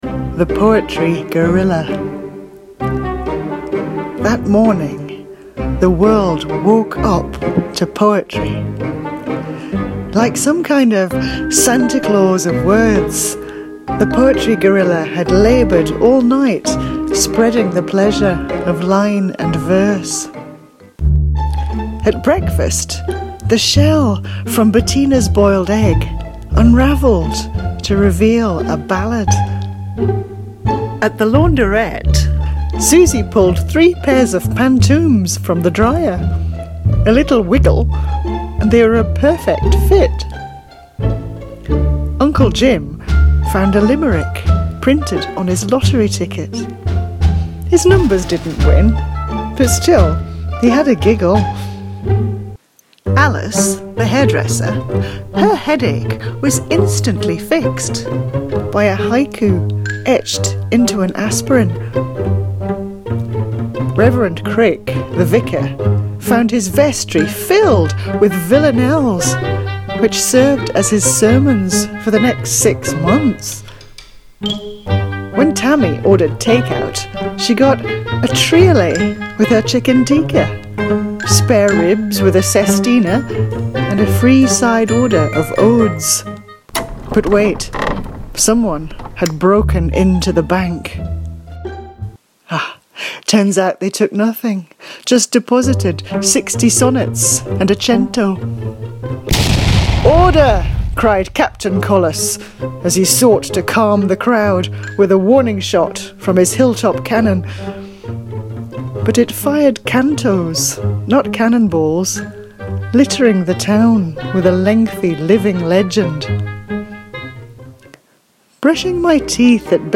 You can listen to me reading it here:
the-poetry-guerrilla_audio.mp3